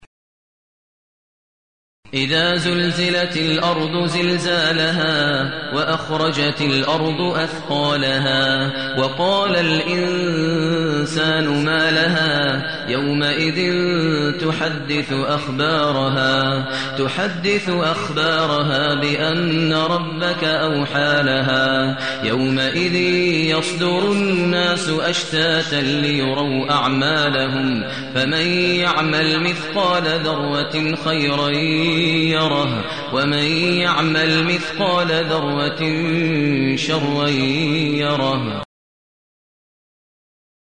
المكان: المسجد الحرام الشيخ: فضيلة الشيخ ماهر المعيقلي فضيلة الشيخ ماهر المعيقلي الزلزلة